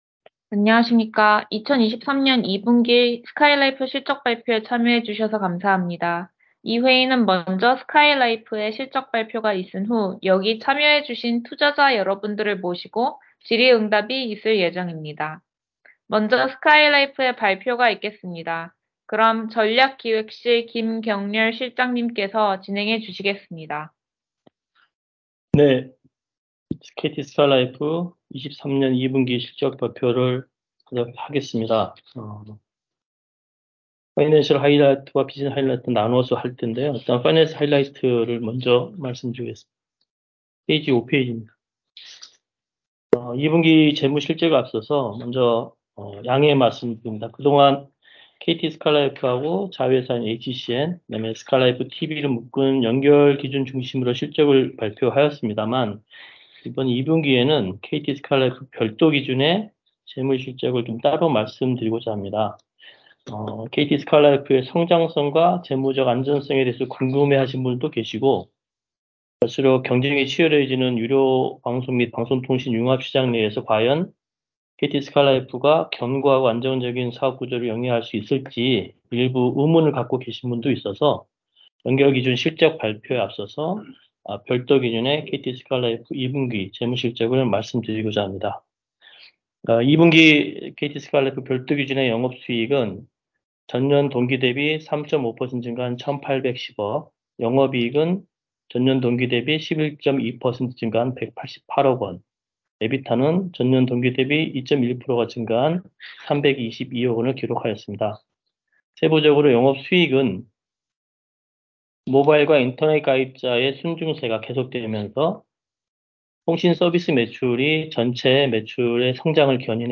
2023년 2분기 실적발표 컨퍼런스콜 녹취록 입니다.